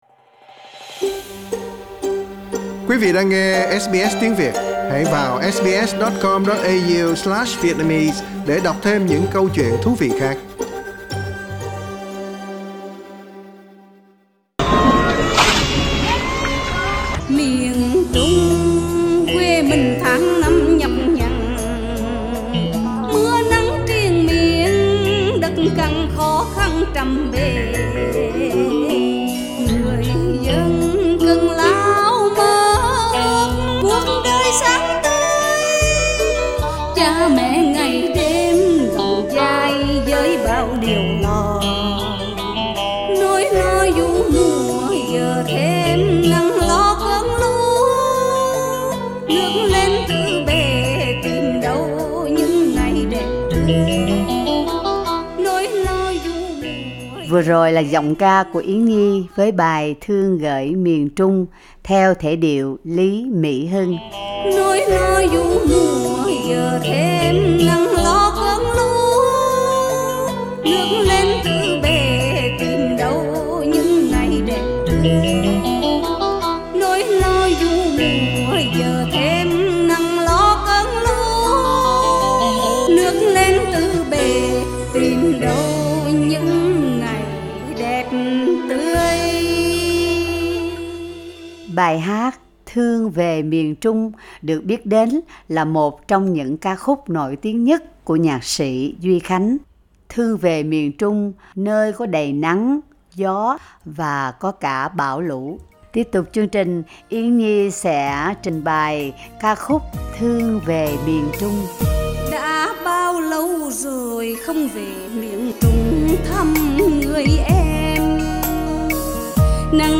Cổ nhạc Việt Nam tại Úc: Thương Về Miền Trung
Anh chị em nghệ sĩ tại Úc Châu cũng góp tiếng trong chương trình đặc biệt 'Thương Về Miền Trung'.